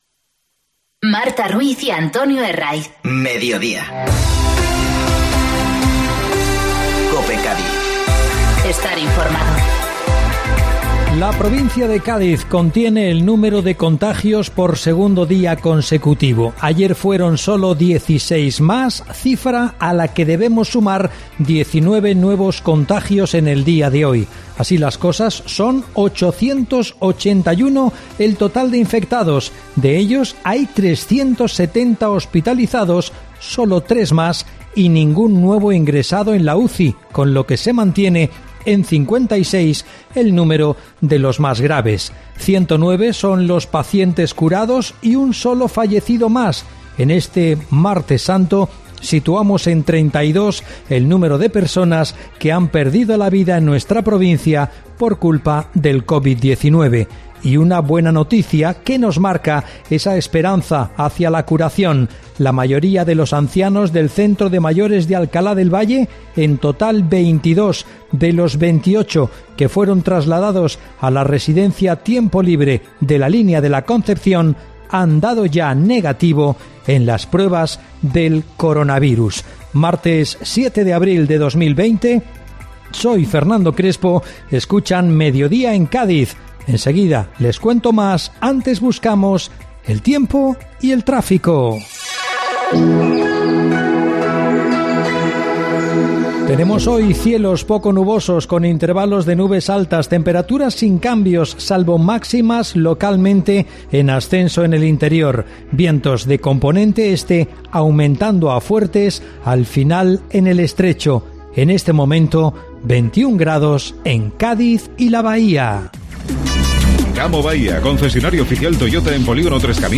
Informativo Mediodía COPE Cádiz (7-4-2020)